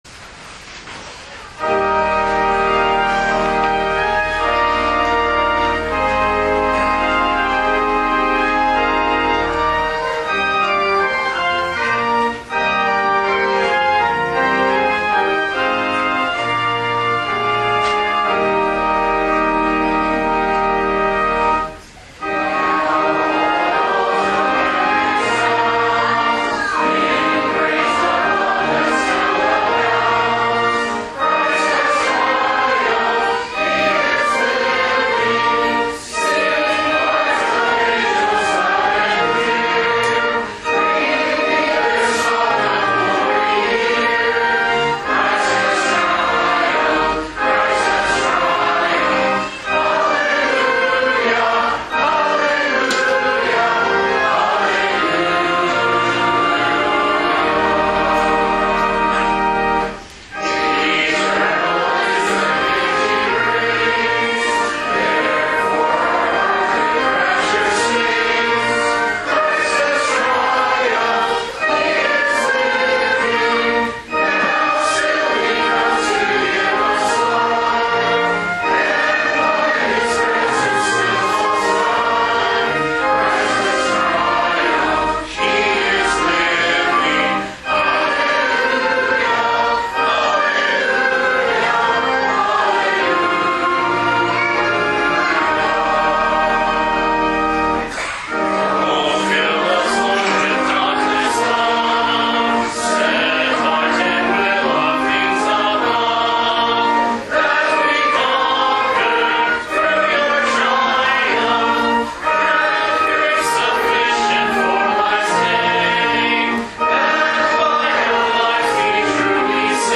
This weeks Sermon Audio